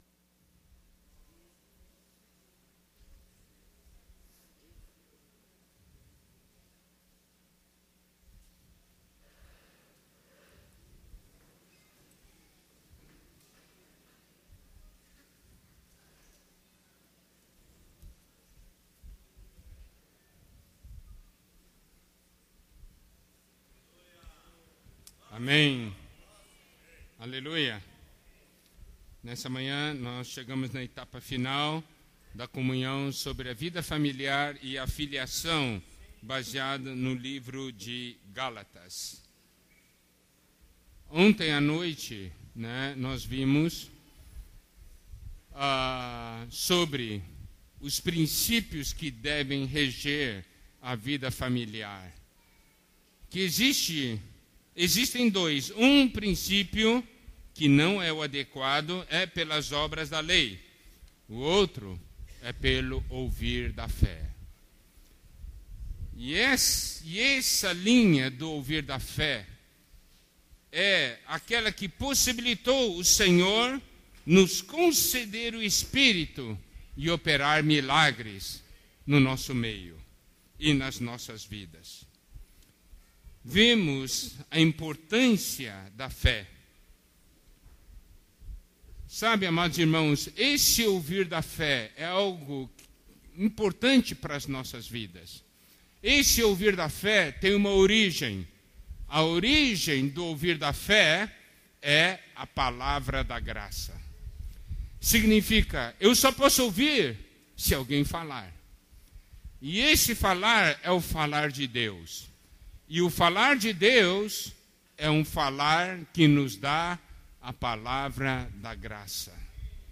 Mensagem Especial